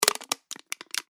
ゴミ | 無料 BGM・効果音のフリー音源素材 | Springin’ Sound Stock
缶をつぶす2.mp3